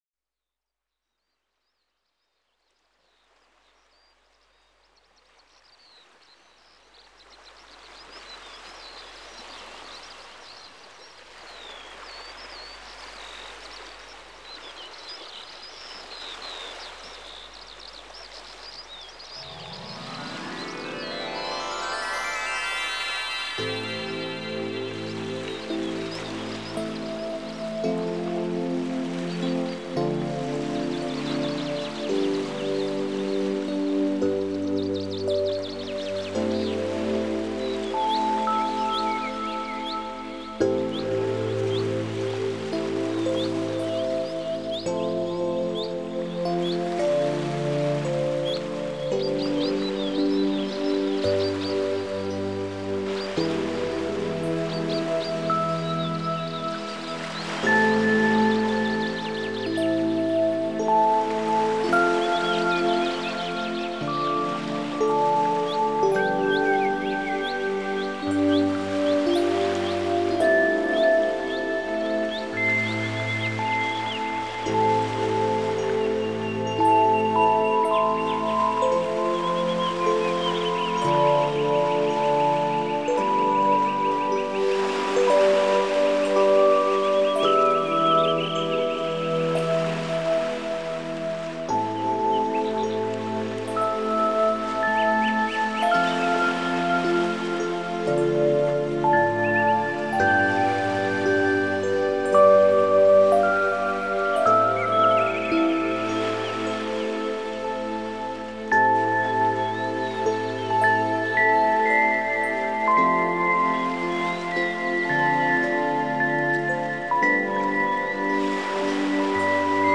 специальностью которой стал мягкий синтезаторный new age.